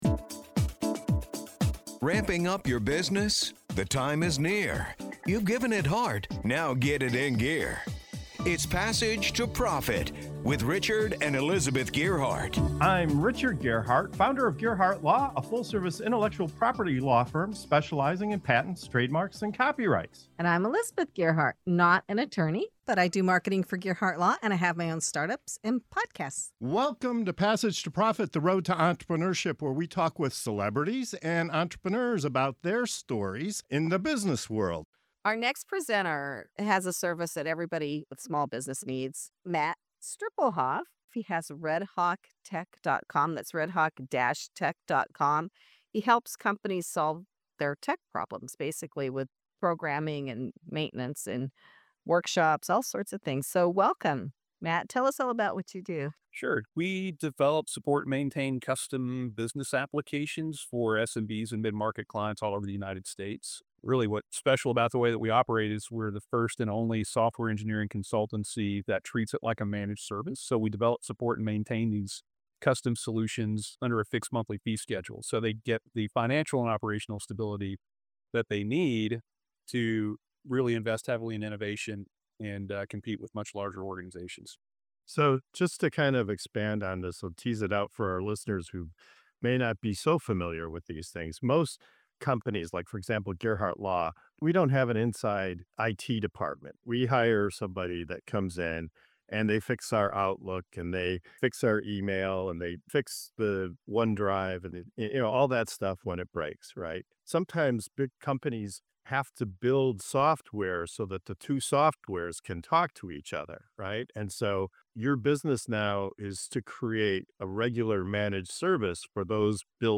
Passage to Profit Show interview